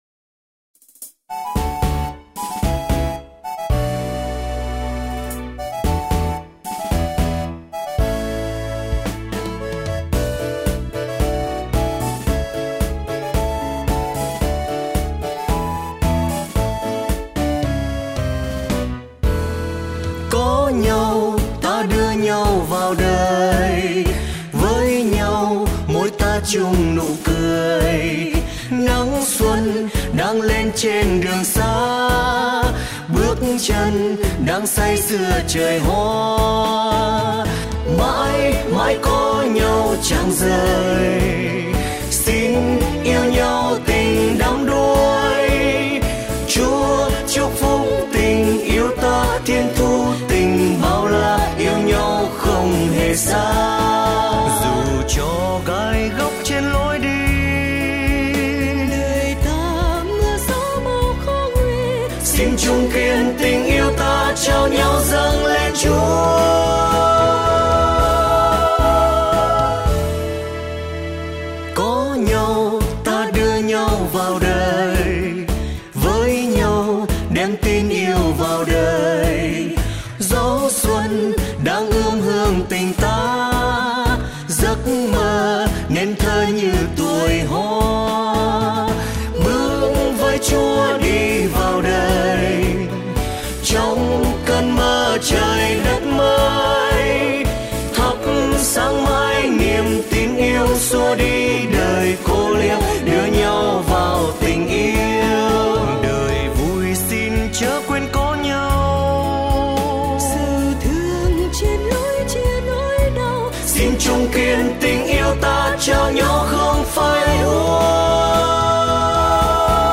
Lễ Cưới